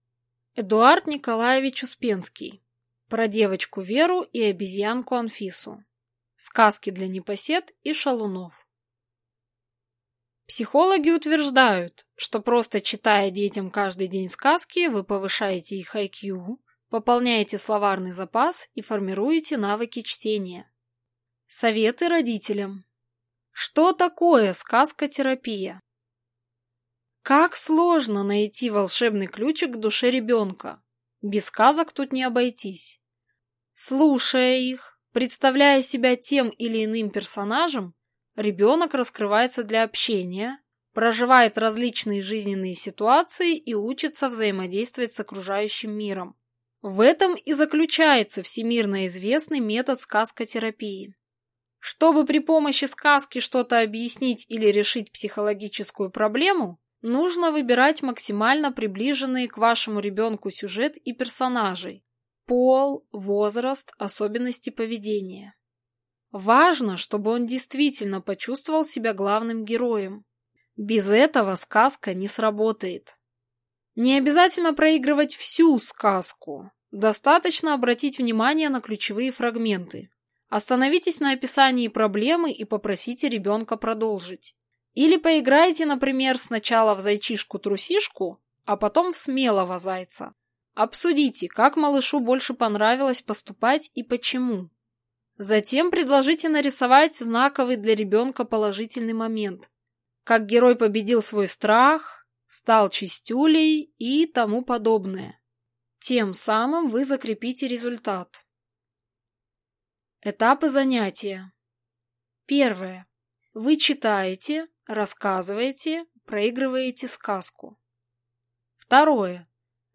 Аудиокнига Про девочку Веру и обезьянку Анфису. Сказки для непосед и шалунов | Библиотека аудиокниг